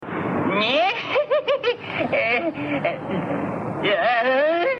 Play Risa Curly Con Miedo - SoundBoardGuy
risa-curly-con-miedo.mp3